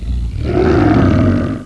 roar1.wav